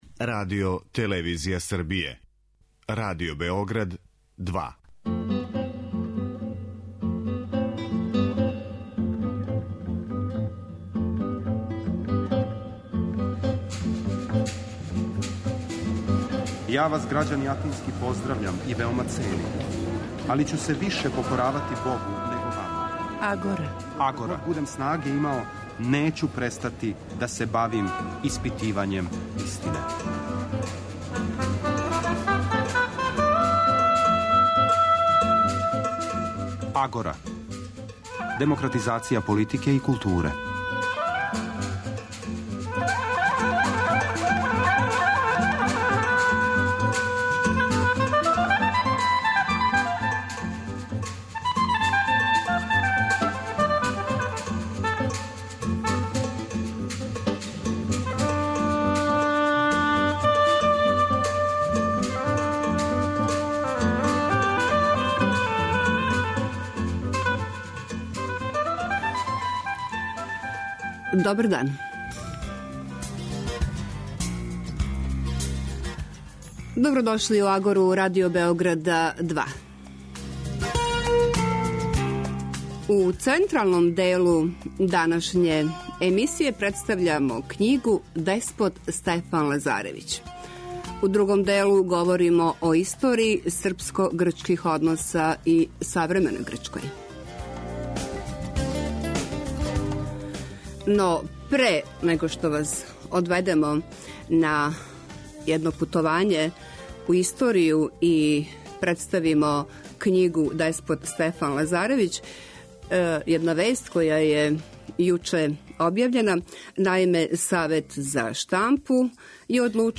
Радио-магазин